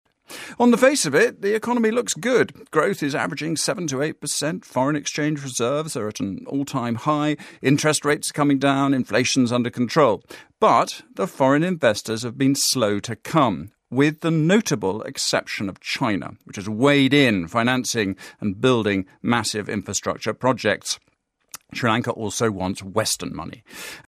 【英音模仿秀】斯里兰卡广拉投资 听力文件下载—在线英语听力室